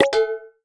menu_dismiss_01.wav